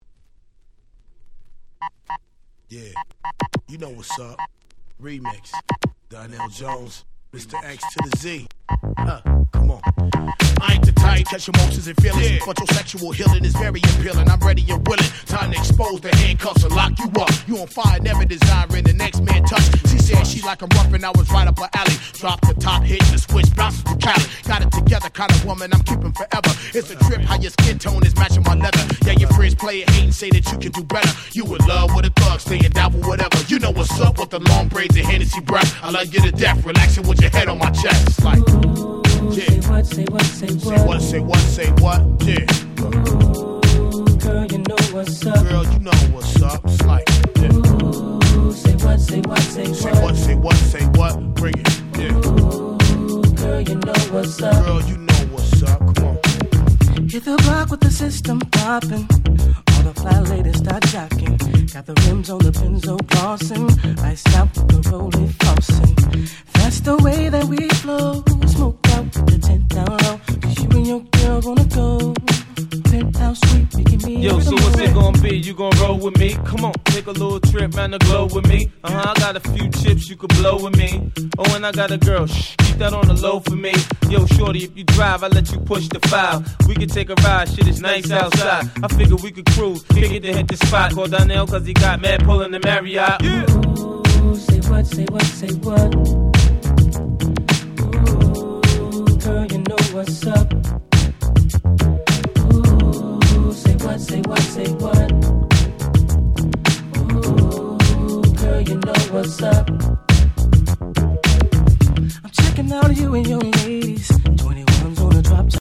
99' Smash Hit R&B !!
イントロにRapの入ったこの盤オンリーのRemix !!
Beatもオリジナルから少し変わっており、「おっ？何このバージョン？」と思わせうる粋なRemixです！